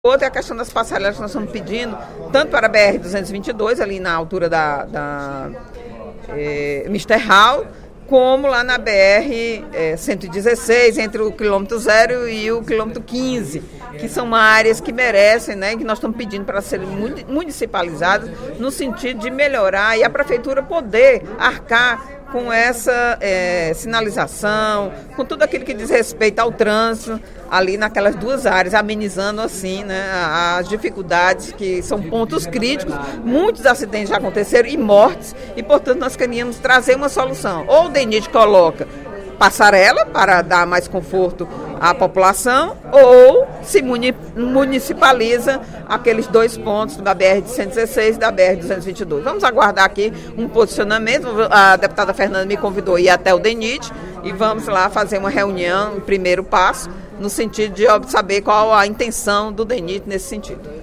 Durante o primeiro expediente da sessão plenária desta terça-feira (15/04), a deputada Eliane Novais (PSB) reclamou do perigo em alguns trechos, localizados em Fortaleza, de rodovias federais.